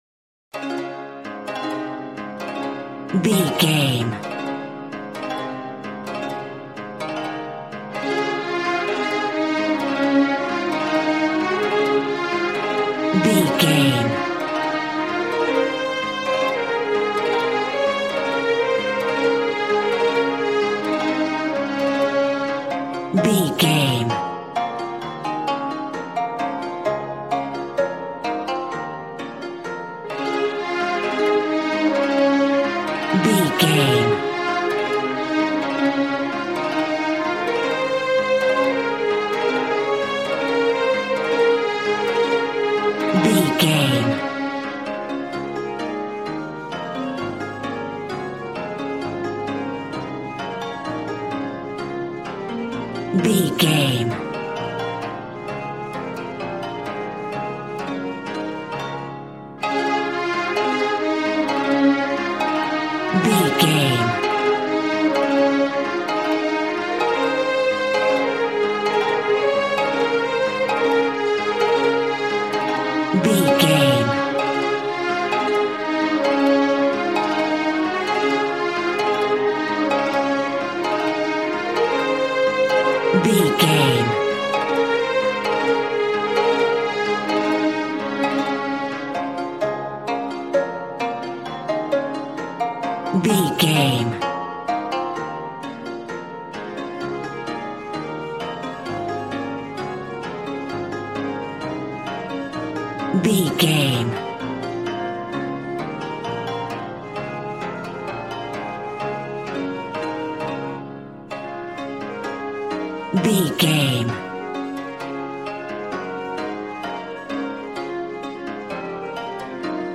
Ionian/Major
A♭
smooth
conga
drums